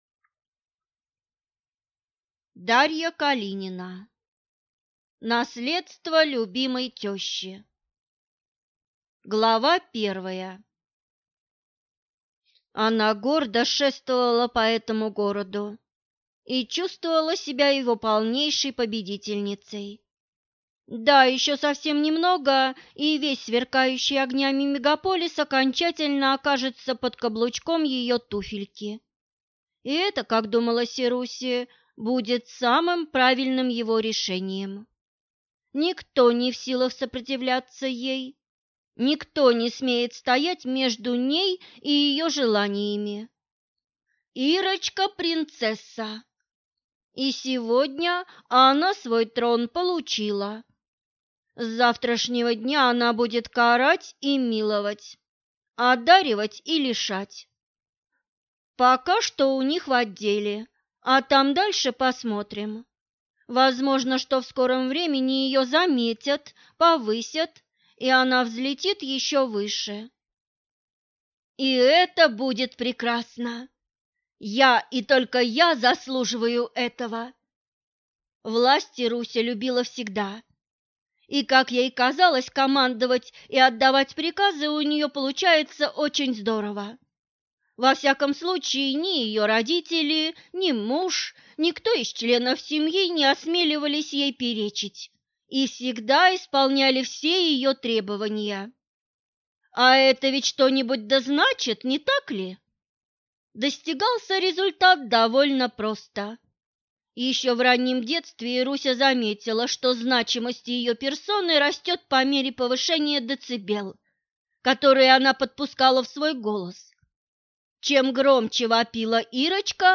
Аудиокнига Наследство любимой тещи | Библиотека аудиокниг